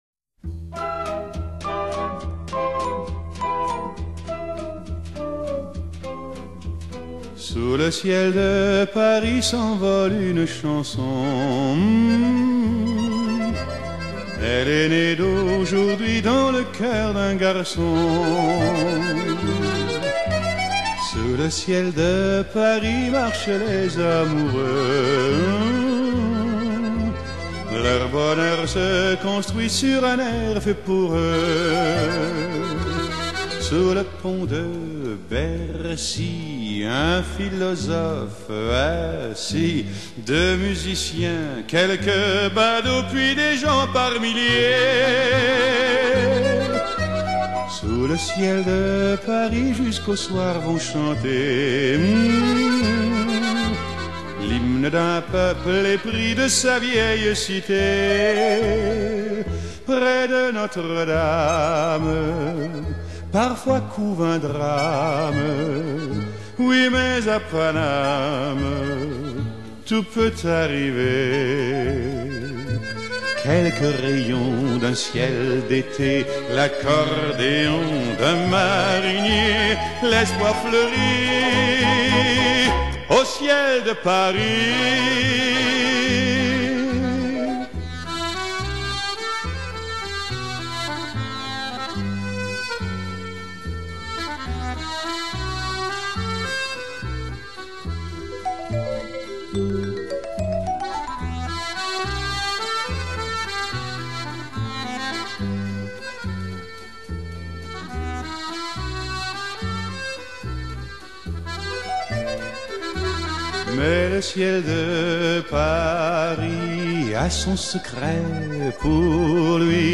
Chanson